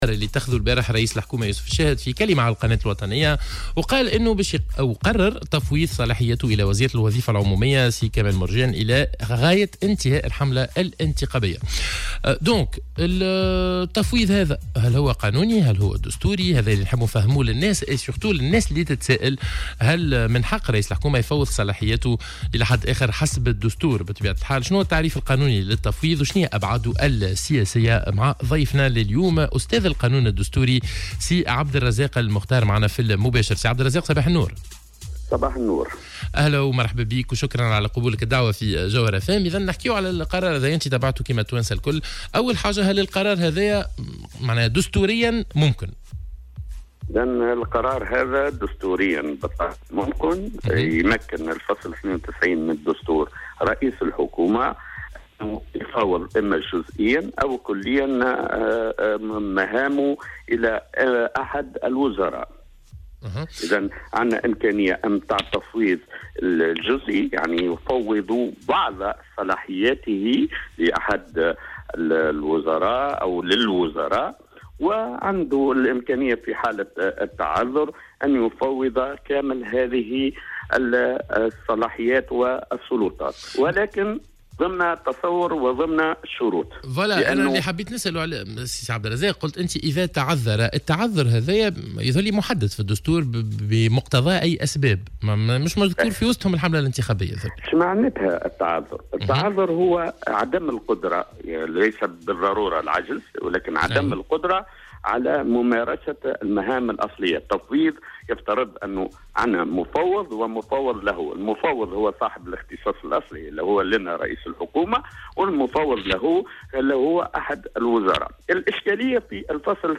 وأضاف في مداخلة له اليوم في برنامج "صباح الورد" على "الجوهرة أف أم" أن الفصل 92 من الدستور ينص على أنه بإمكان رئيس الحكومة أن يفوّض جزئيا أو كليا مهامه إلى أحد الوزراء لكن وفق شروط منها عدم القدرة على ممارسة المهام الأصلية.